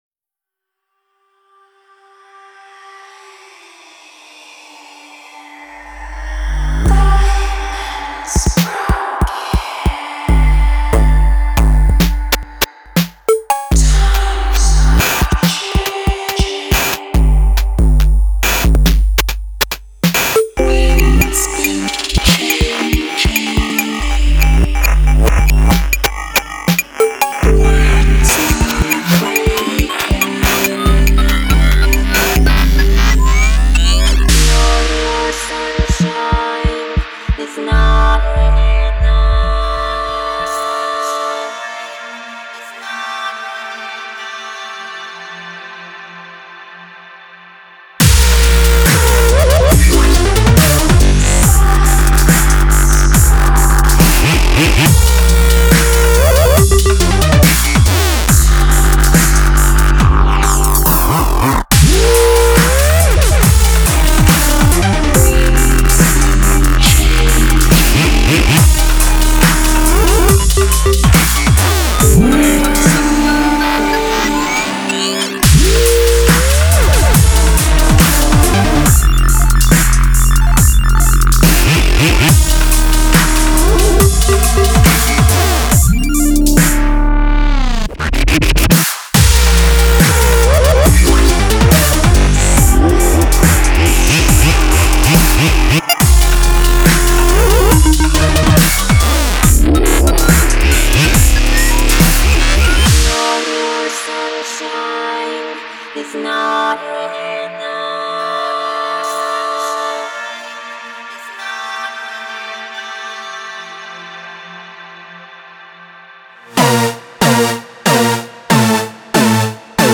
Style: Drum and Bass, Dubstep